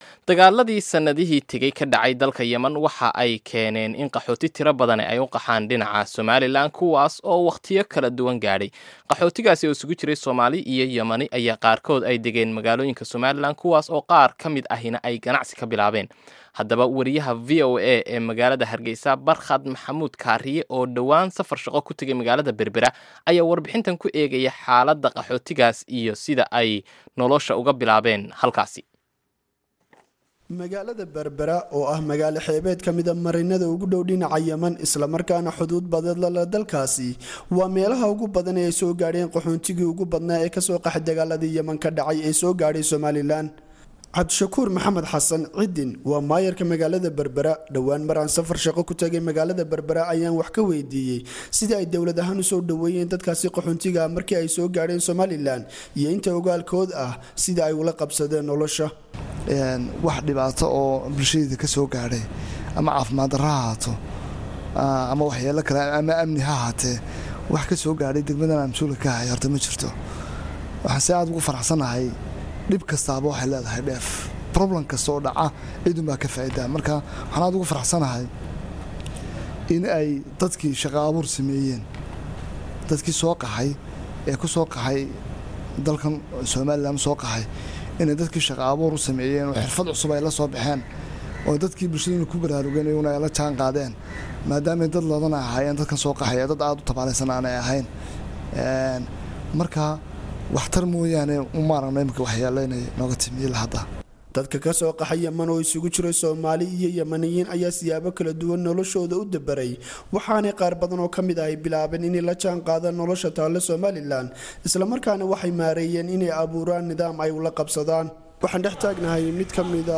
Warbixin: Xaaladda Qaxootiga Yemen ee Somaliland